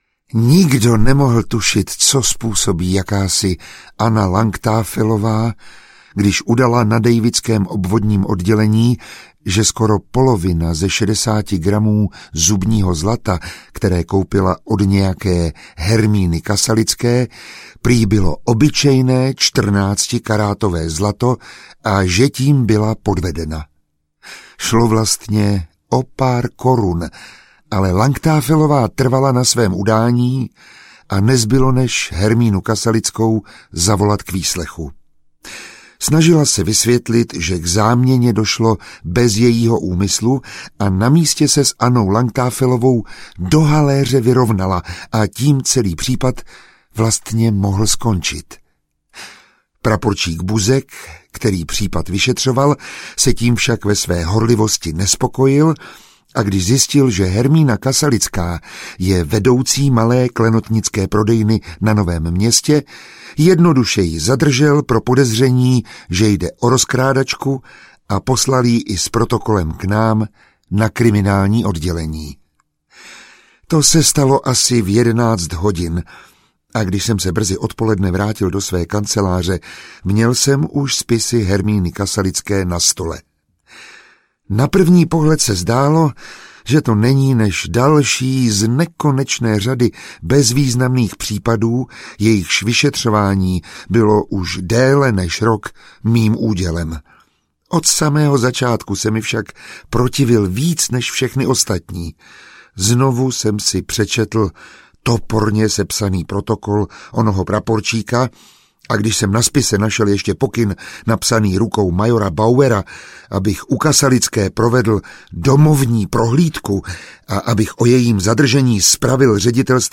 Dlouhý stín času audiokniha
Ukázka z knihy